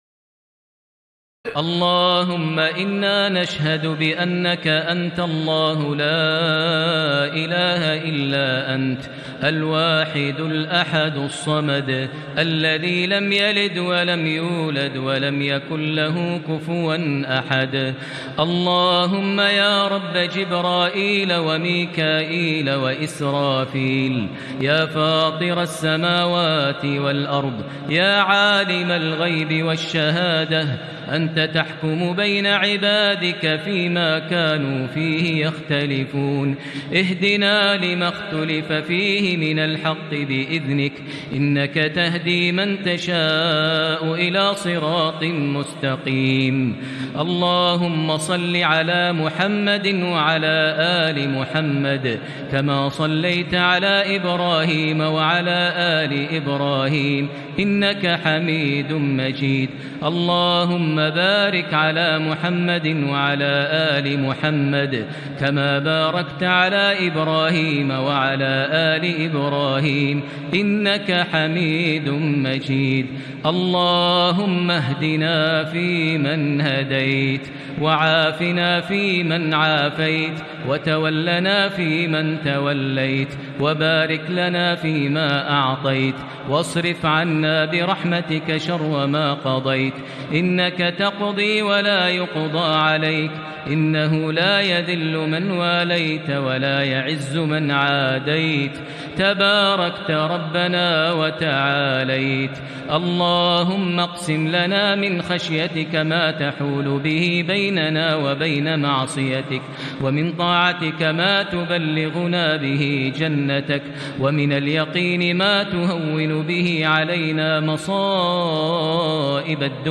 دعاء القنوت ليلة 19 رمضان 1440هـ | Dua for the night of 19 Ramadan 1440H > تراويح الحرم المكي عام 1440 🕋 > التراويح - تلاوات الحرمين